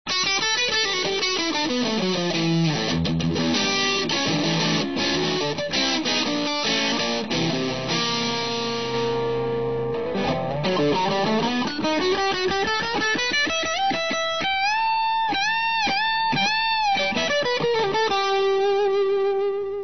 FUZZ-BOXES
SLOWFINGER FUZZTONE TEXT t